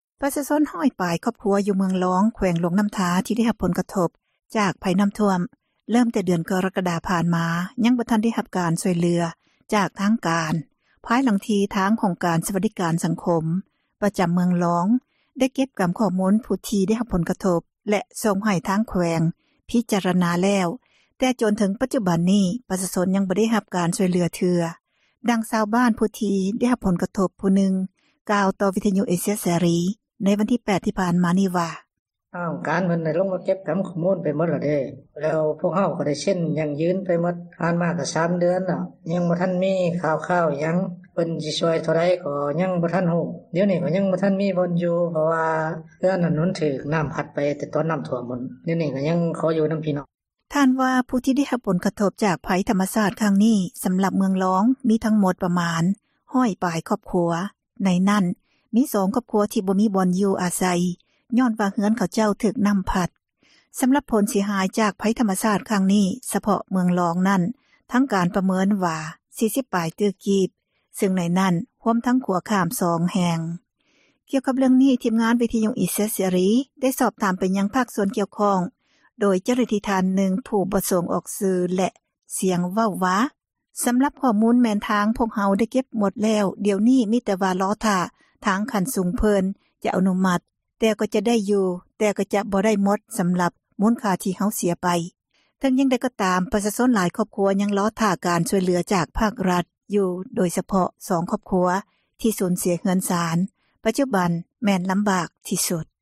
ດັ່ງ ຊາວບ້ານ ຜູ້ທີ່ໄດ້ຮັບຜົນກະທົບ ກ່າວຕໍ່ວິທຍຸເອເຊັຽເສຣີ ໃນ ວັນທີ 8 ຕຸລາ ນີ້ວ່າ: